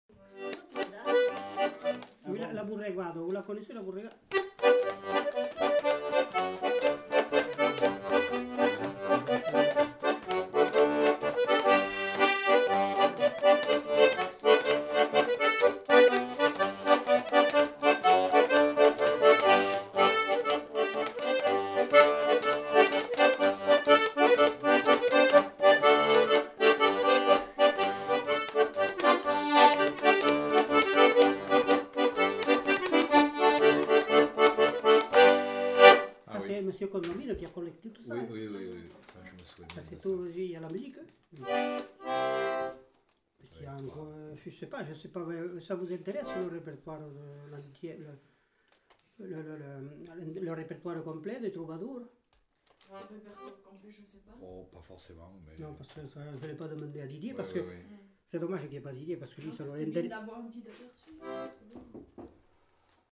Aire culturelle : Lomagne
Lieu : Pessan
Genre : morceau instrumental
Instrument de musique : accordéon chromatique
Danse : borregada